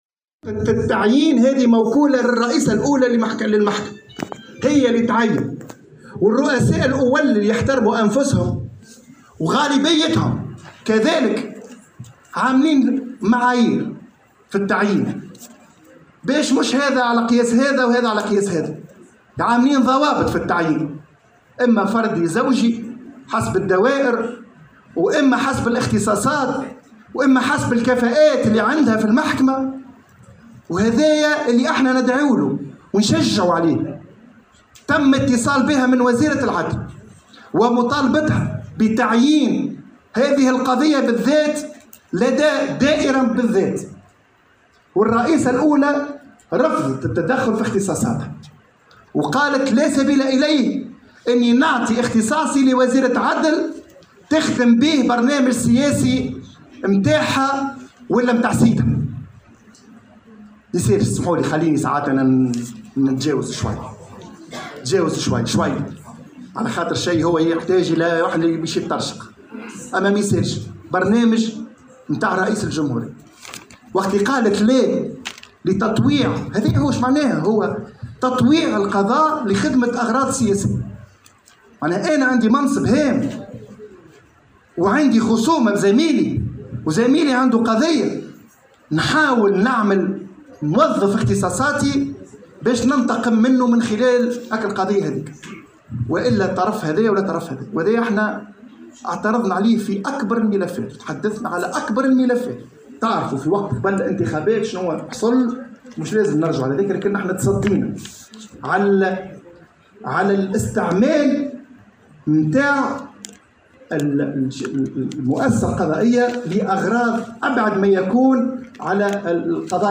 في ندوة صحفية عقدتها الجمعية اليوم الخميس